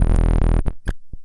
描述：电子敲击式低频摩擦鼓
标签： 低音 电子 摩擦滚筒 敲击
声道立体声